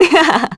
Juno-Vox-Laugh_kr.wav